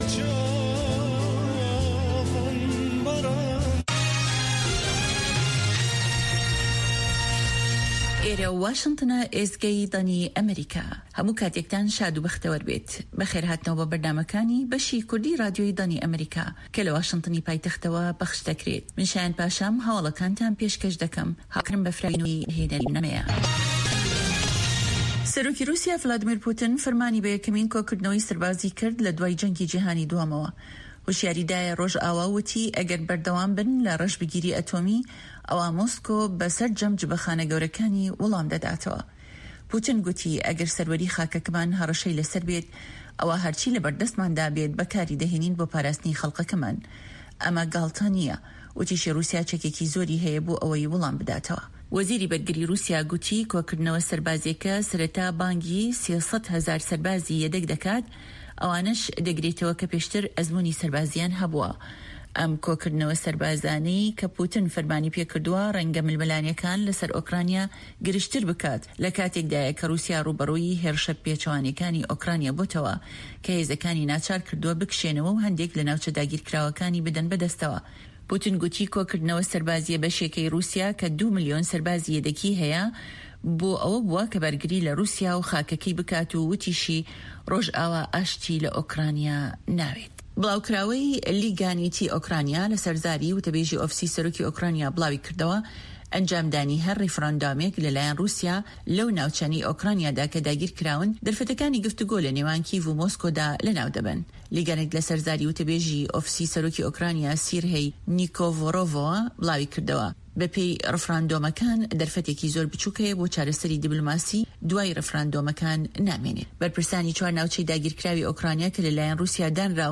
هەواڵە جیهانییەکان 2